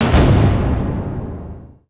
quest_success.wav